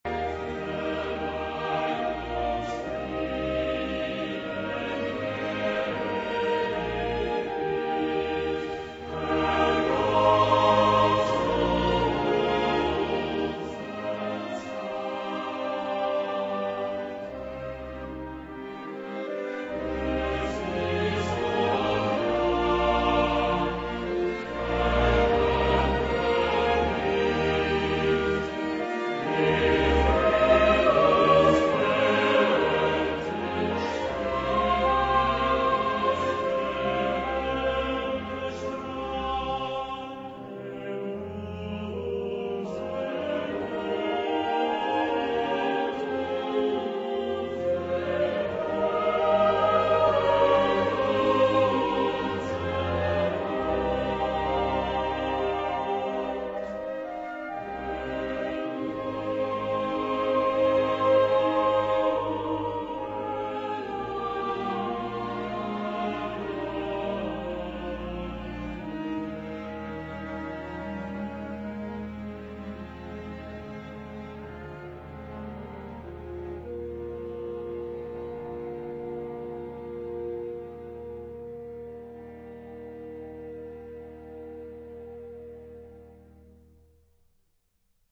Mood of the piece: lyric ; beautiful ; moving ; prayerful
Type of Choir: SATB  (4 mixed voices )
Instrumentation: Orchestra  (12 instrumental part(s))
Tonality: E flat major